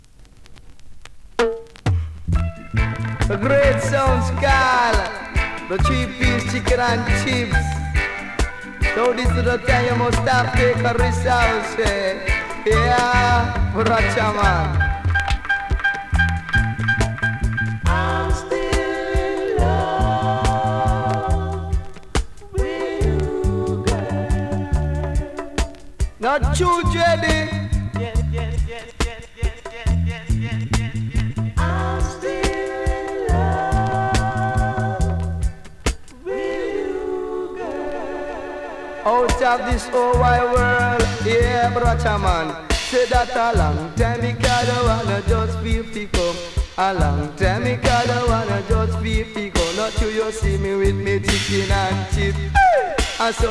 SKA〜REGGAE
DJ!!